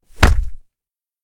0014_一脚踹倒.ogg